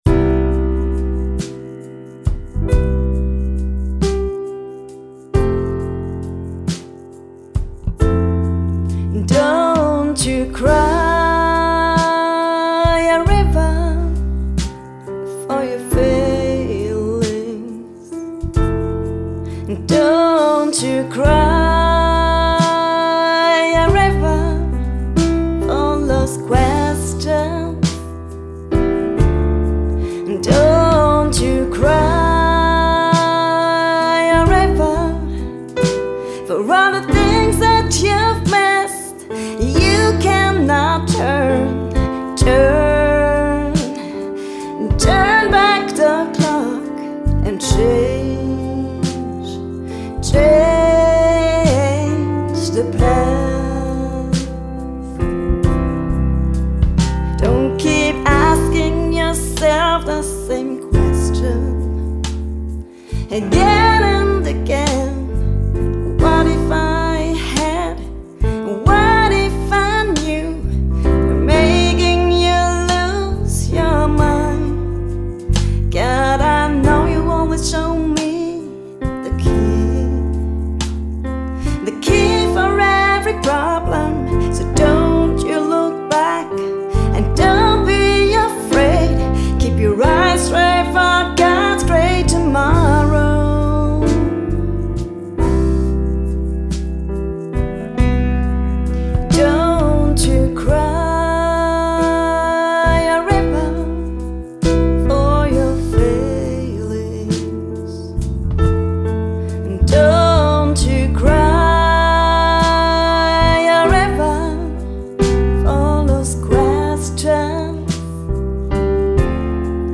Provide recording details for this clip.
The songs were written and recorded under a tight time schedule, so these are just workshop recordings.